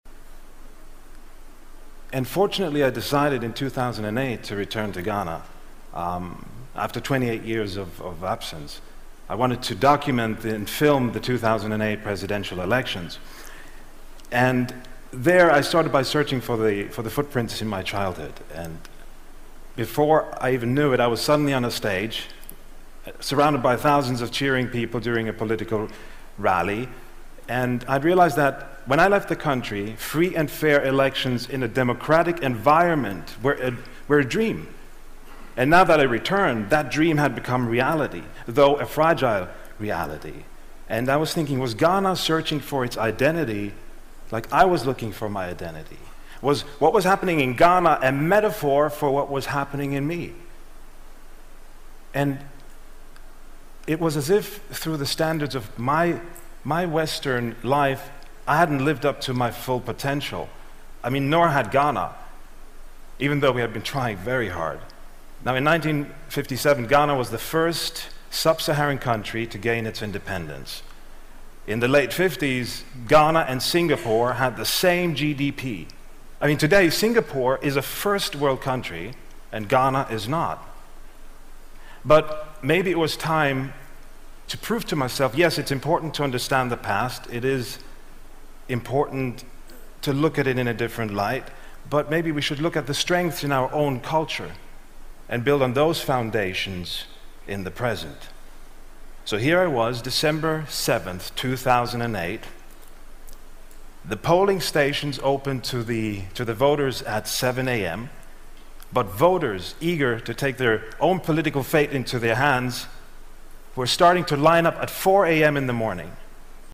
TED演讲:影片纪录加纳民主进程(2) 听力文件下载—在线英语听力室